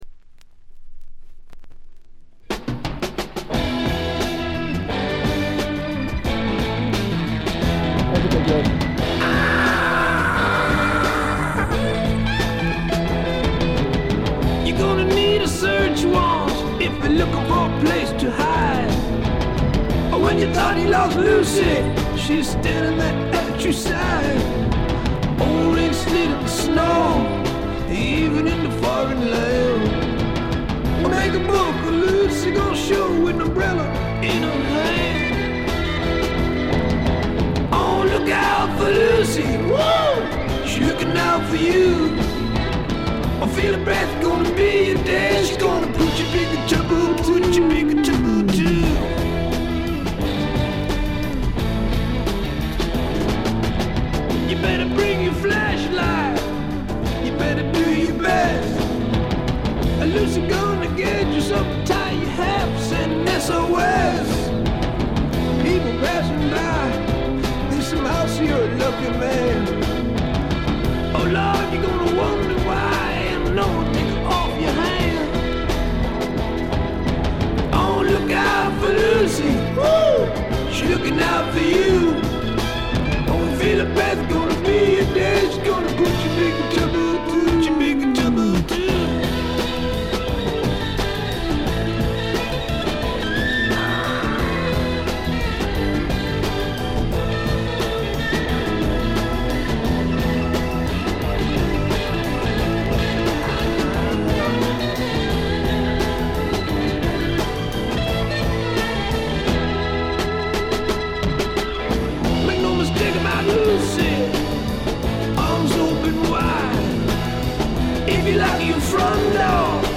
静音部（ほとんどないけど）でチリプチ少々、散発的なプツ音が2-3回出たかな？って程度。
試聴曲は現品からの取り込み音源です。